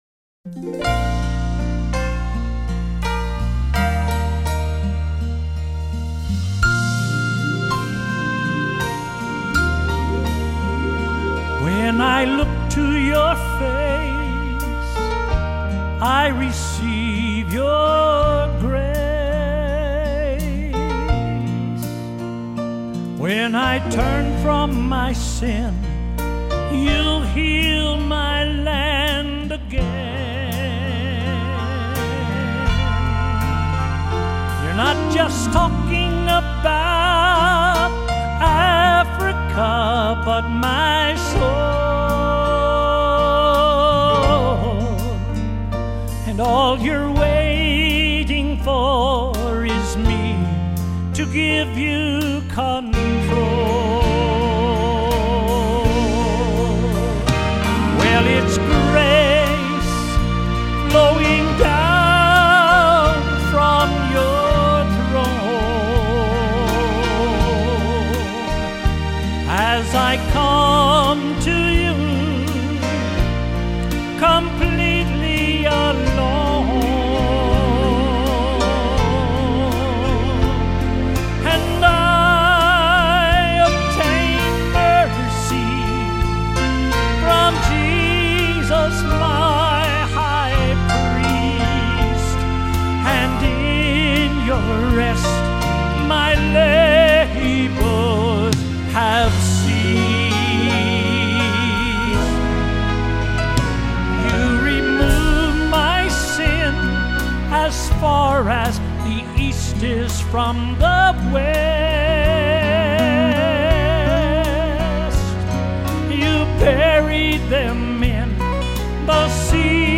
Southern Gospel Songwriter